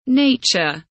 nature kelimesinin anlamı, resimli anlatımı ve sesli okunuşu